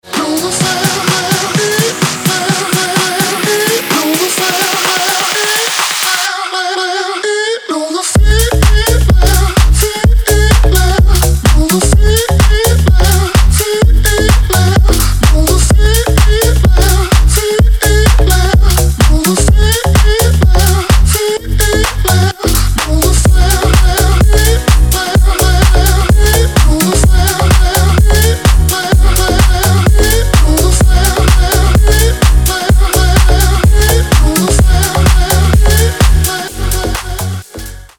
• Качество: 320, Stereo
house
Заводной Хаус